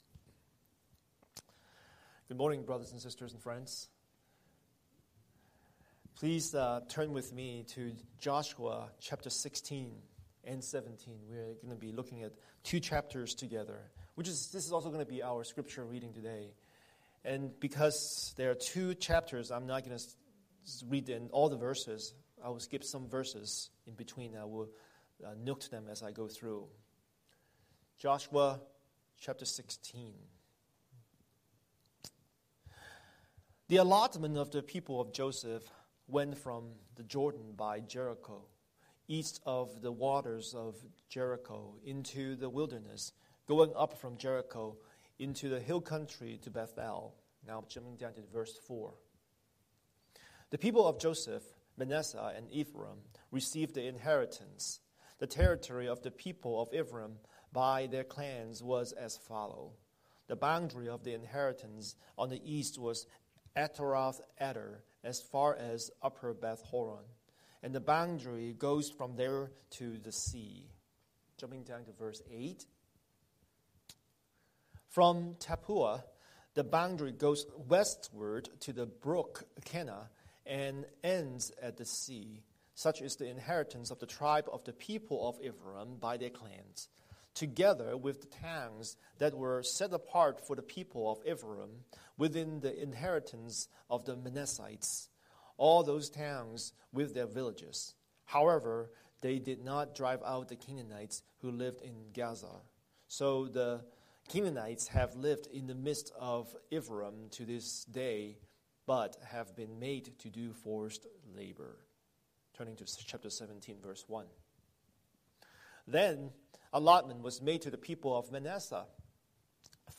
Scripture: Joshua 16:1–17:18 Series: Sunday Sermon